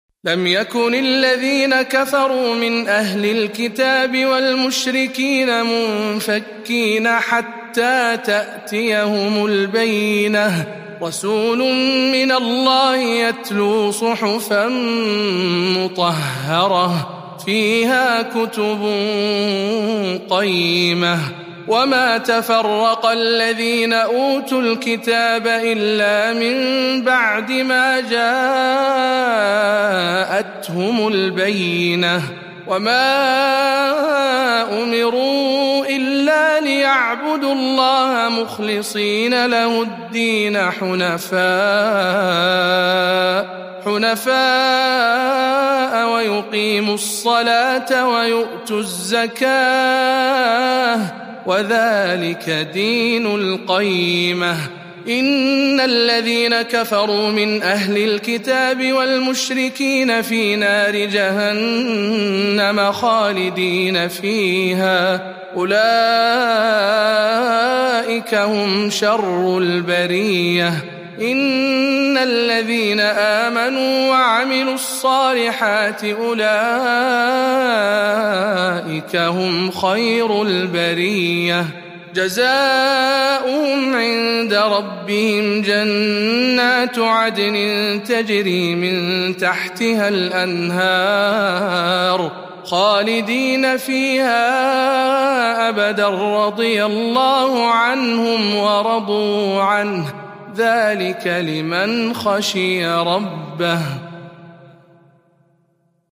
097. سورة البينة برواية شعبة عن عاصم